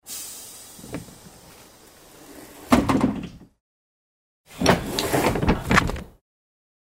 Двері автобуса відчиняються, зачиняються